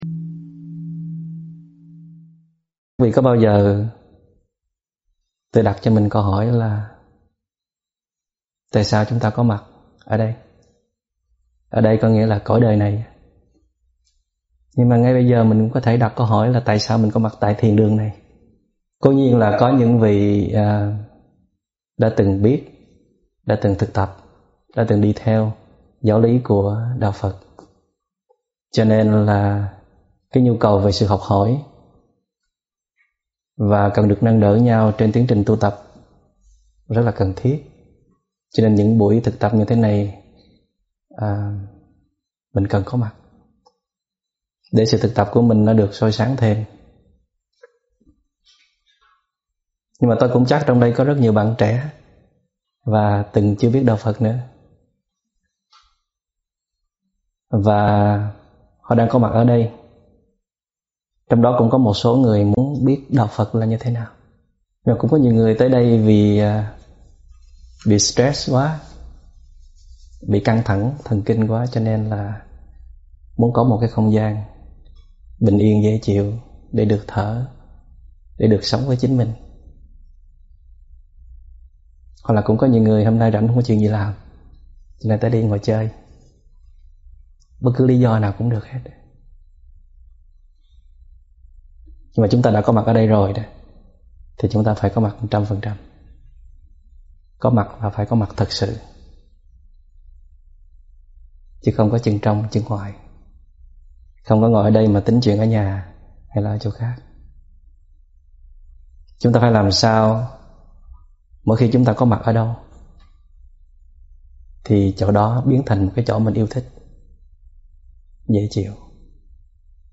Thích Minh Niệm giảng Mp3 Thuyết Pháp Thuyết pháp Thích Minh Niệm